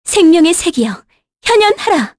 Talisha-Vox_Skill6_kr.wav